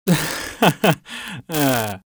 Sarcastic Laugh Male
Sarcastic Laugh Male.wav